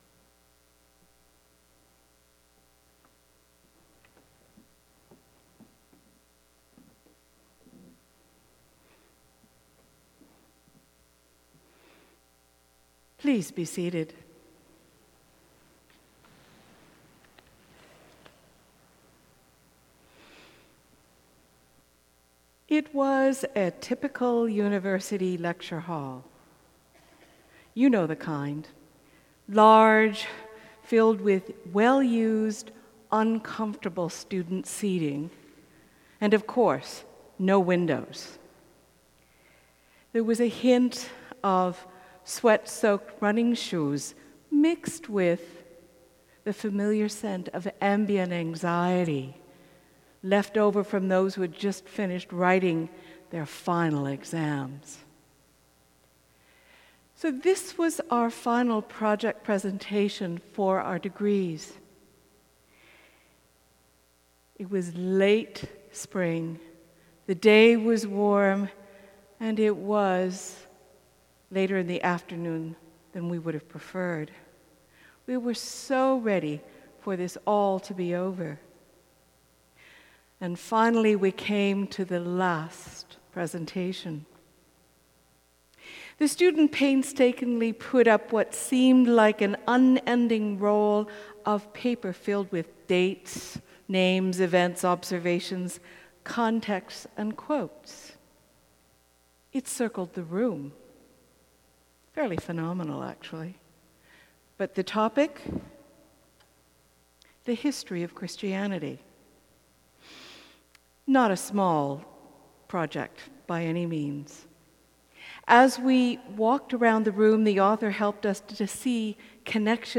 Sermon: 11.00 a.m. service